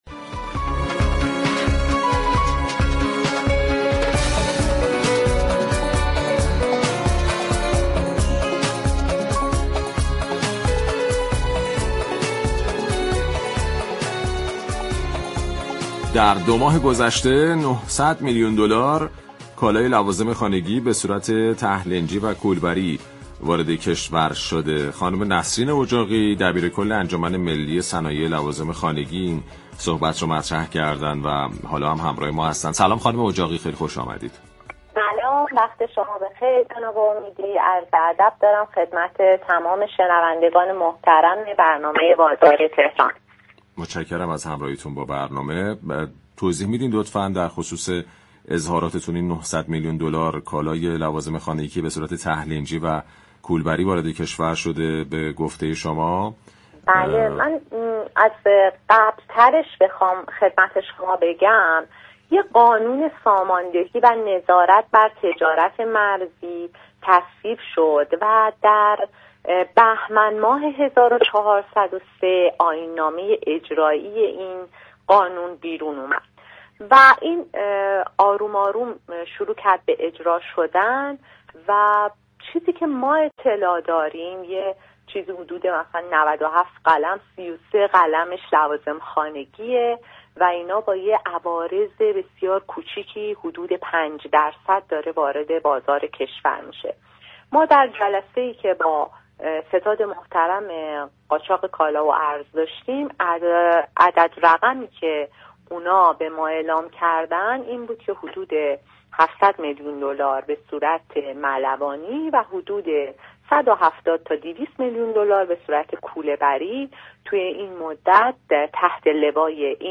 در گفتگو با برنامه «بازار تهران»